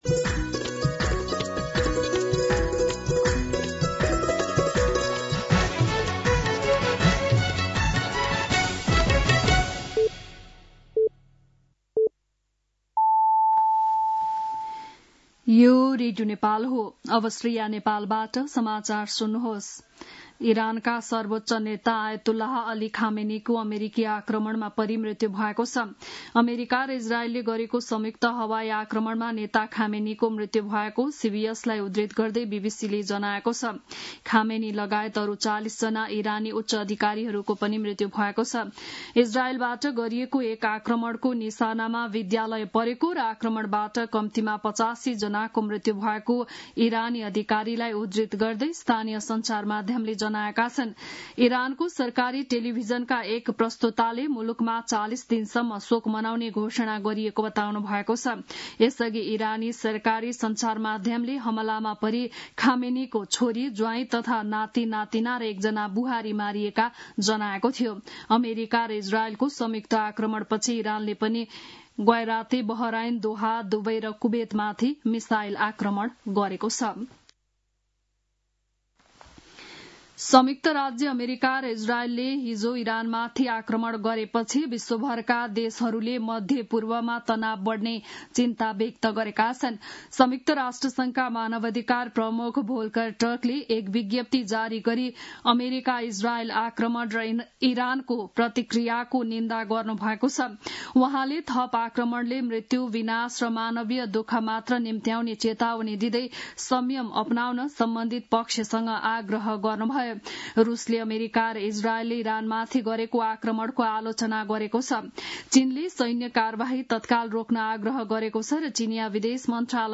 बिहान ११ बजेको नेपाली समाचार : १७ फागुन , २०८२
11am-News-17.mp3